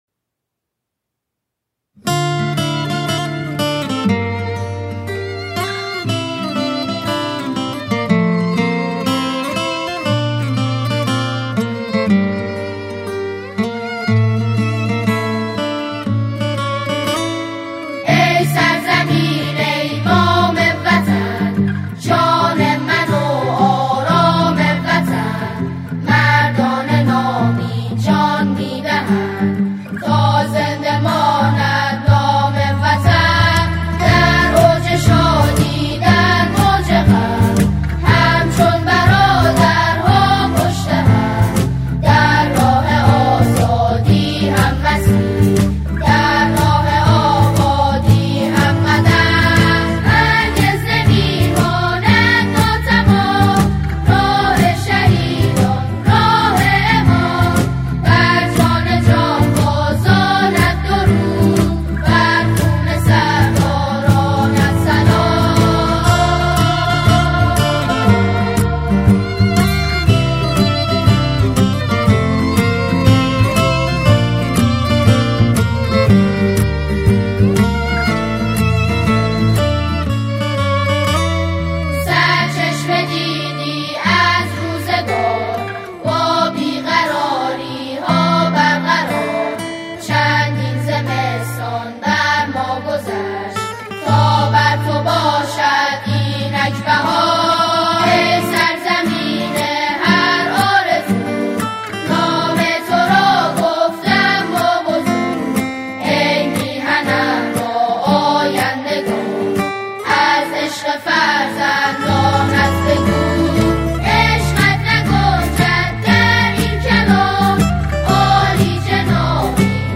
سرودهای انقلابی